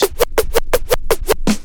Scratch 2.wav